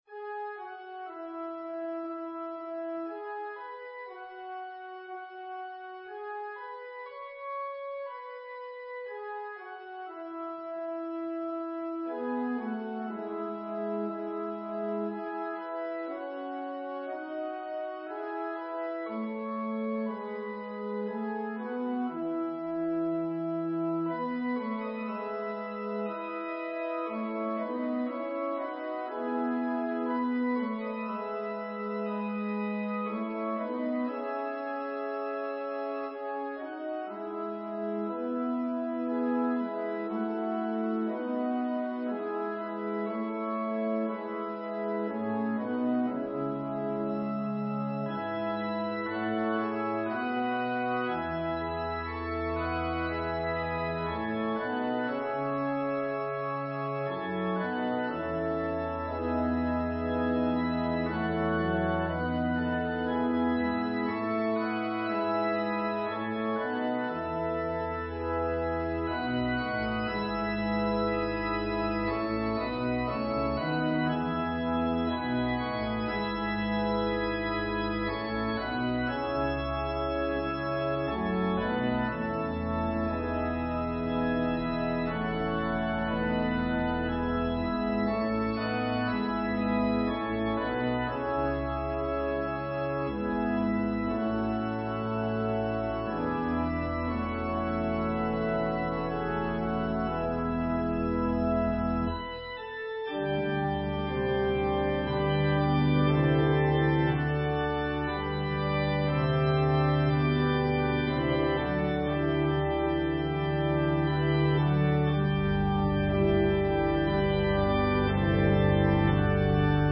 An organ solo version in the original key of E Major